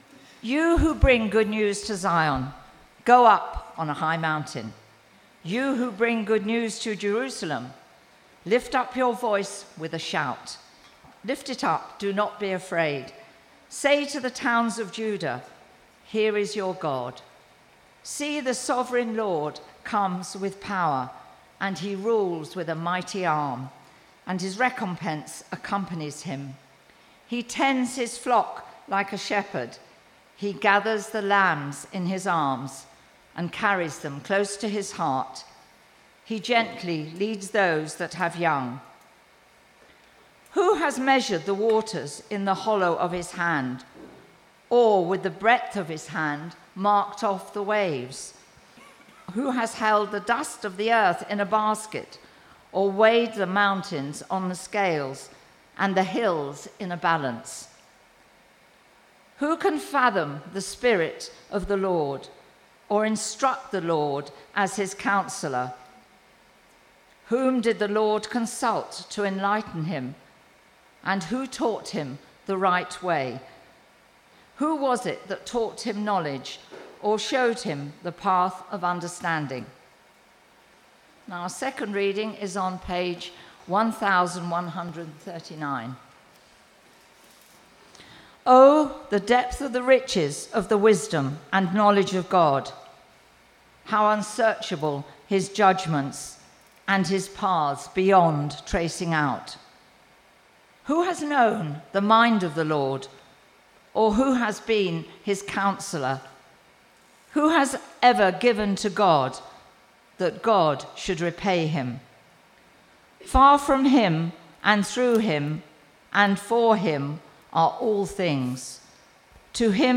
Media for Christ Church Morning Service on Sun 29th Jun 2025 10:30
Theme: Sermon